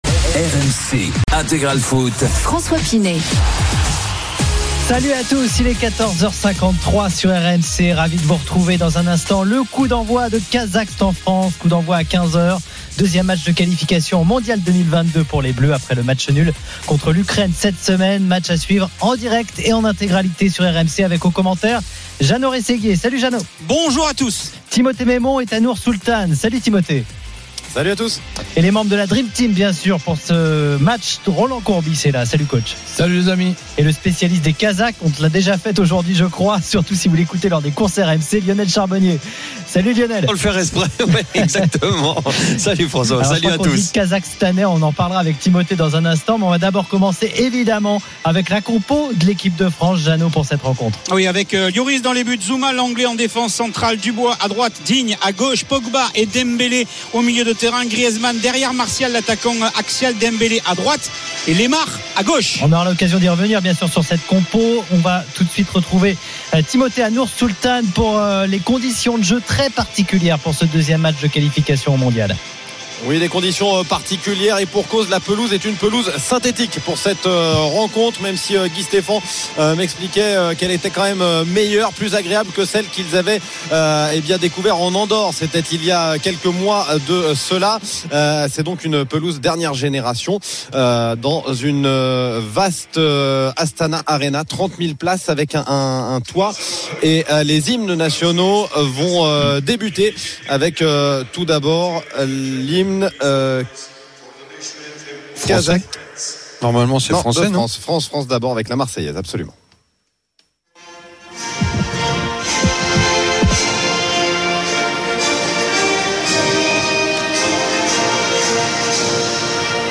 Les grands événements sportifs du week-end en direct !
Live, résultats, interviews, analyses, ...